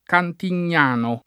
Cantignano [ kantin’n’ # no ] top. (Tosc.)